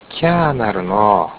下関弁辞典
発音